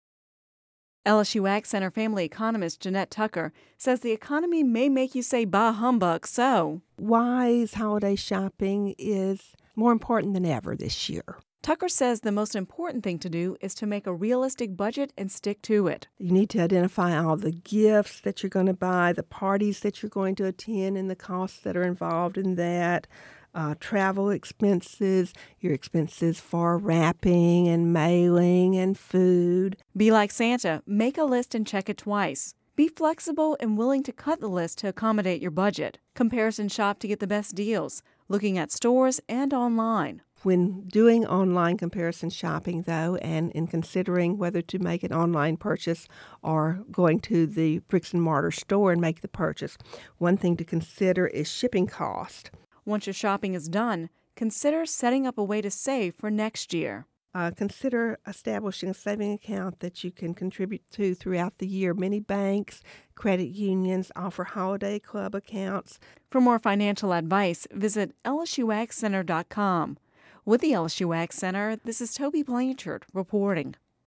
Radio News 12/06/10